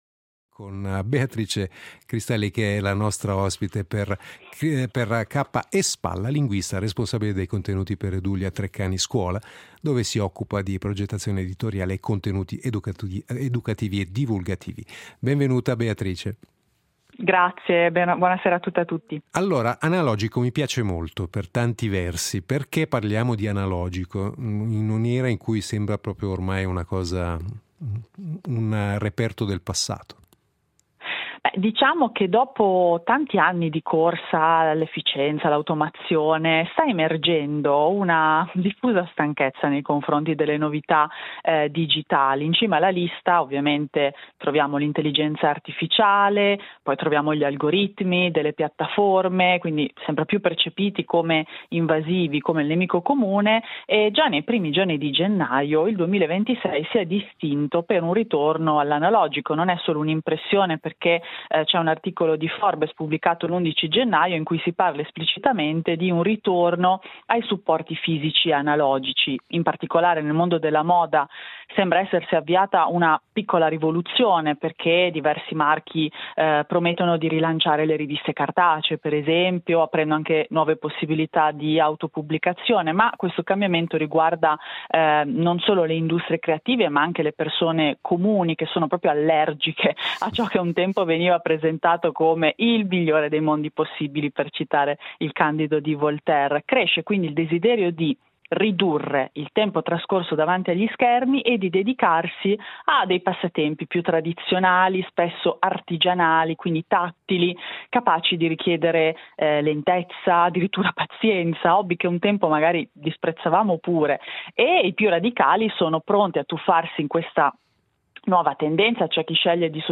L’editoriale del giorno